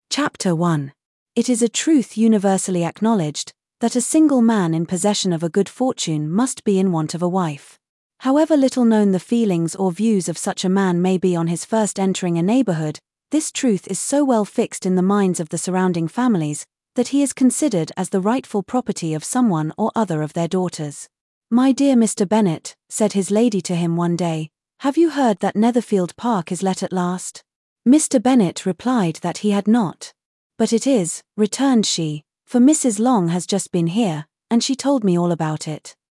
We selected the Neural voice for the three following services: Microsoft Azure, Google Gemini, Amazon Polly while selecting the standard voices for Eleven Labs’ and OpenAI.
• Microsoft Azure Sample:
Microsoft_Azure_clip.mp3